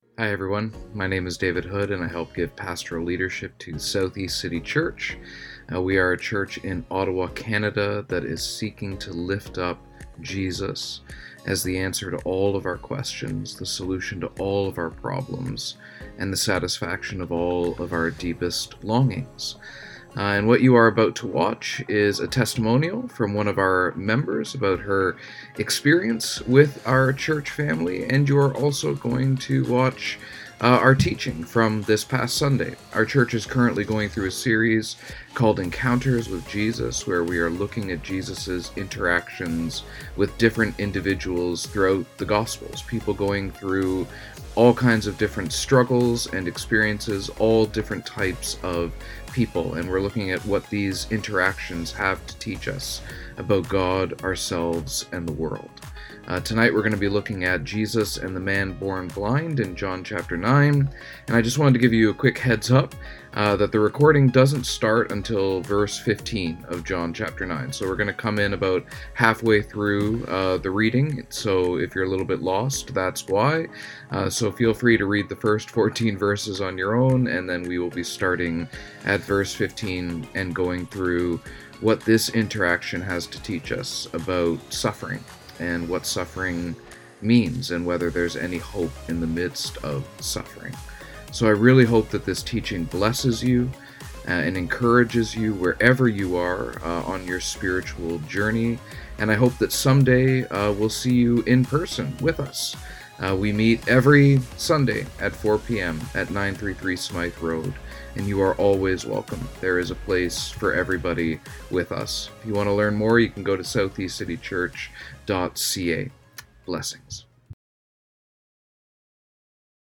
2024 Jesus and Thomas Preacher